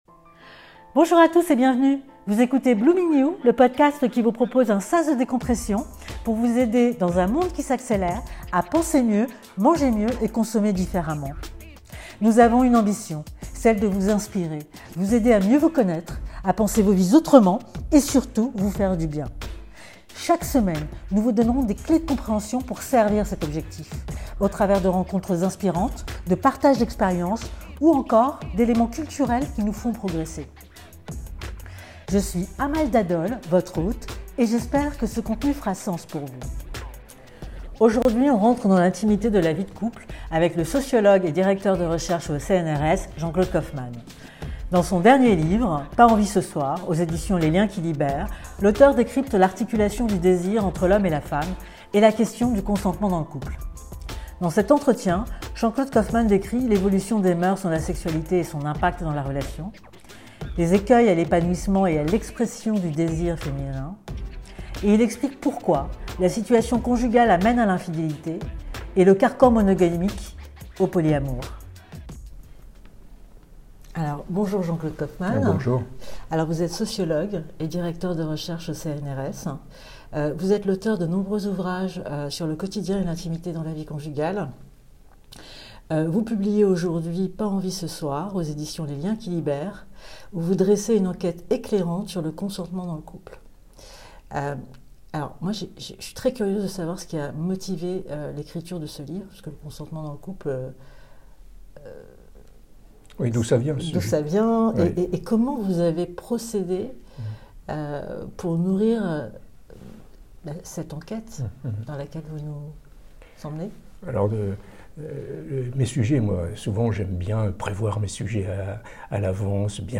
Rencontre avec un sociologue de la vie de couple